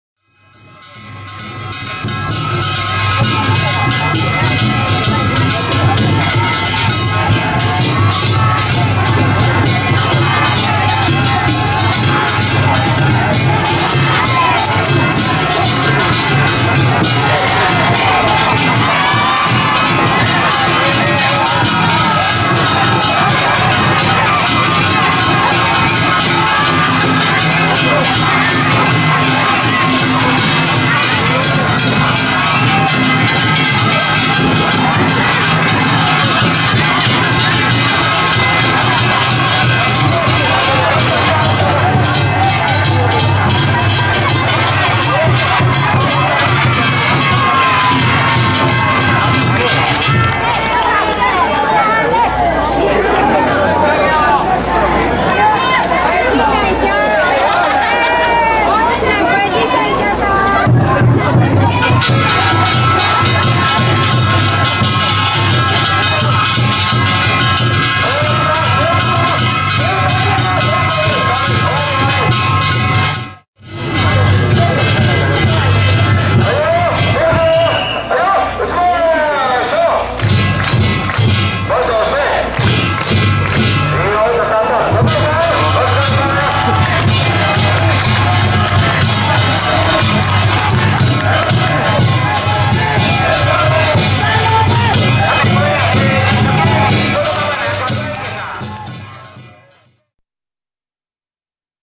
平成２９年８月２日、尼崎市の貴布禰神社地車山合わせを見に行ってきました。
周りの観客からも歓声と拍手喝采でした！